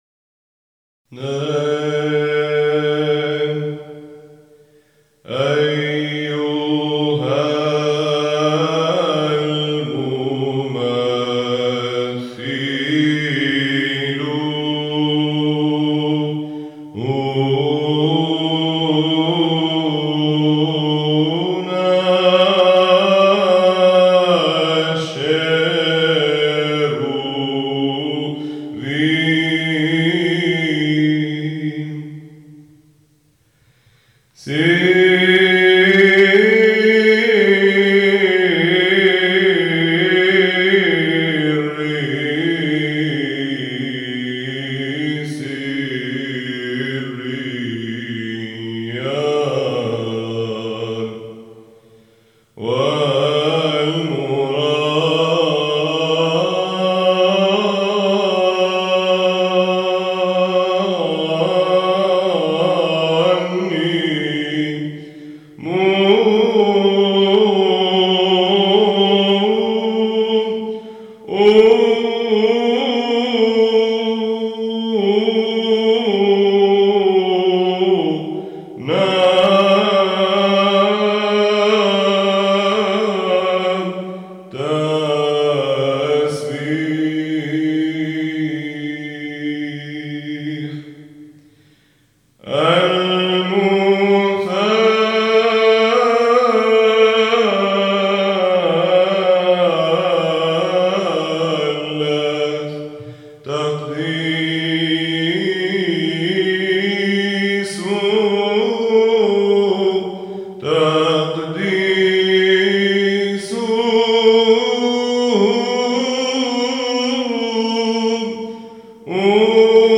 19-  الشيروبيكون باللّحن الثاني